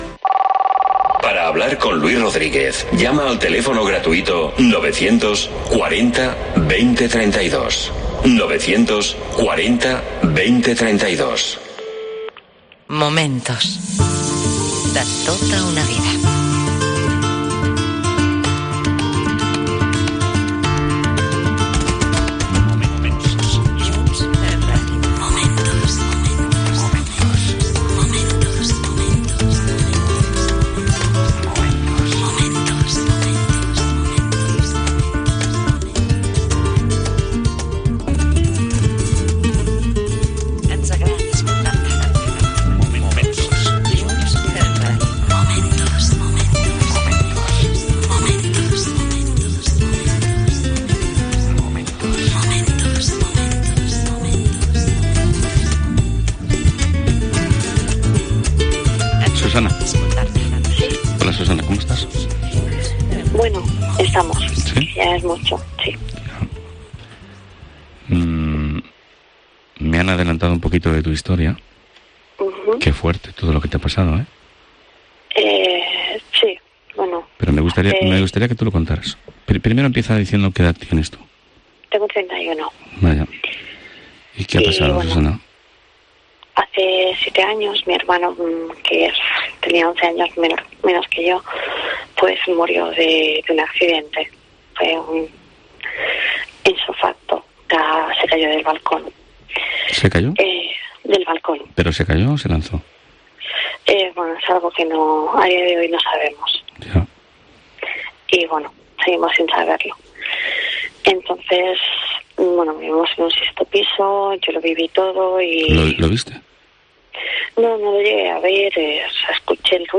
'MOMENTOS' Radio de madrugada